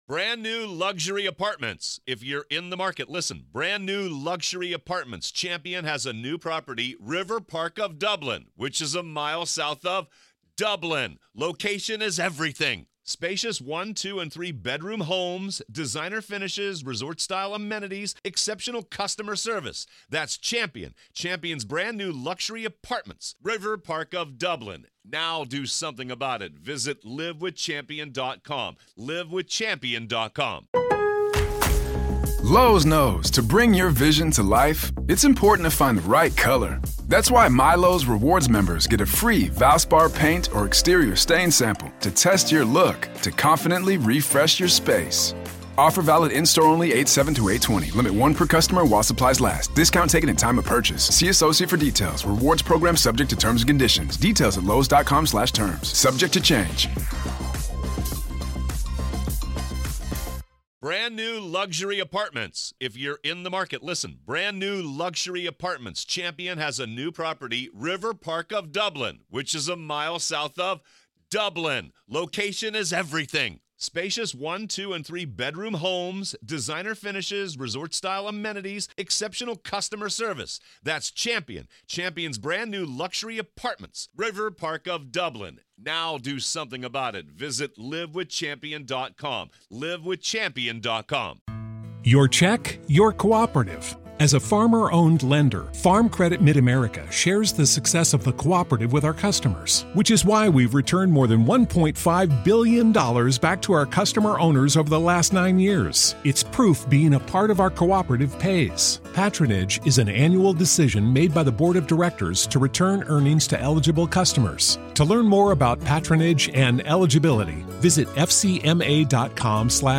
Listen To The Full Preliminary Hearing Of Chad Daybell, Part 9